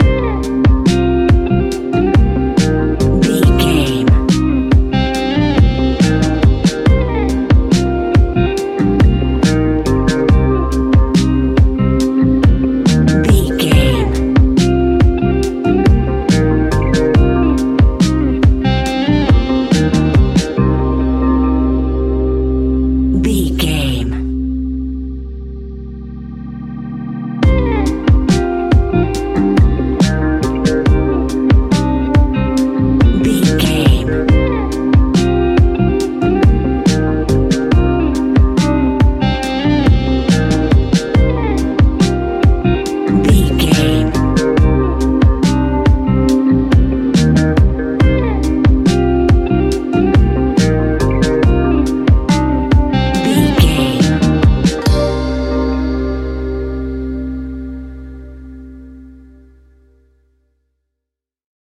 Ionian/Major
C♭
chilled
laid back
Lounge
sparse
chilled electronica
ambient
atmospheric
morphing